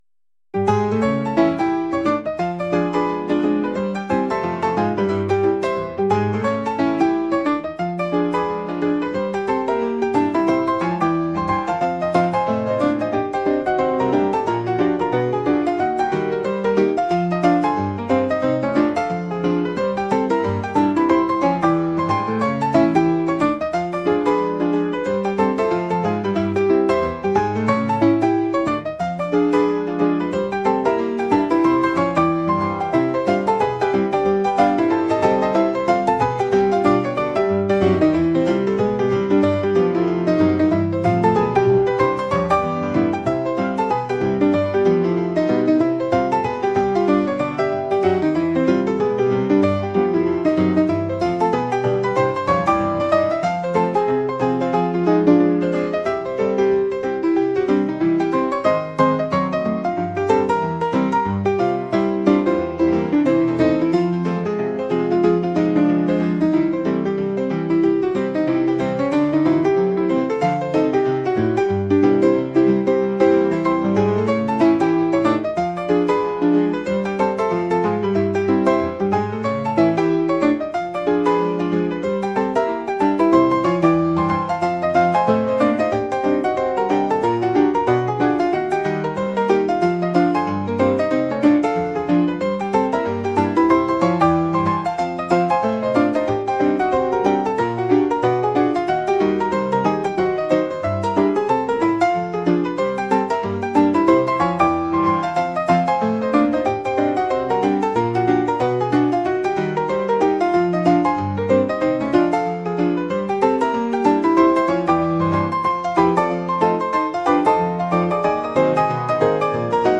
jazz | lively